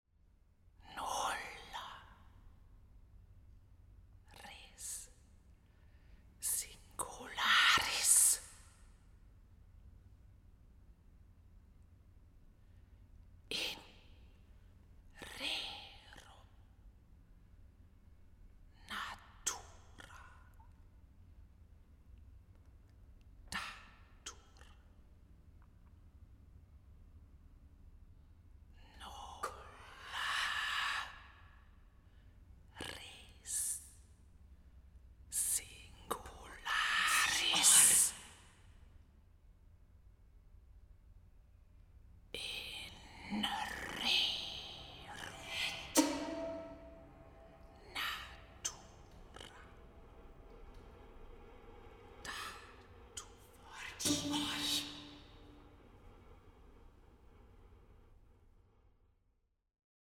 Auch in Dolby Atmos
Vokalensemble
Aufnahme: University of Bergen, Norway, 2024